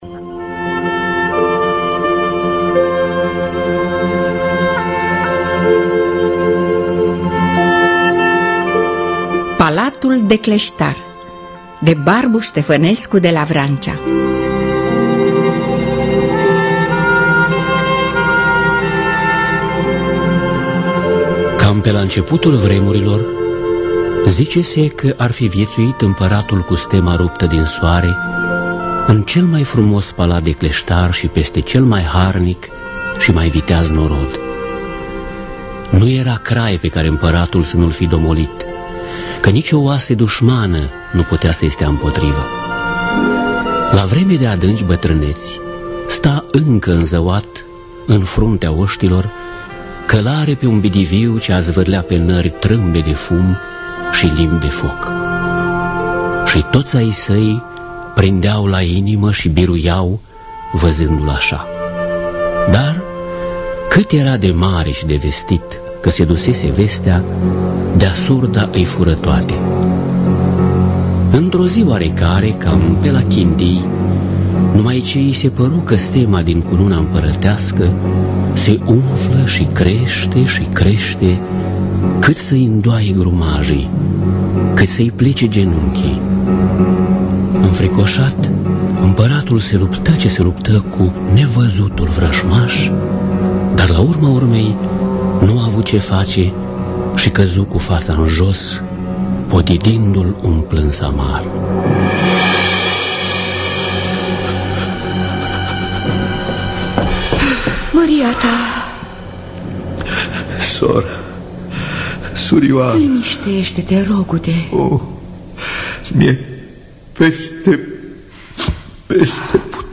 Dramatizarea radiofonică de Mihai Jemăneanu.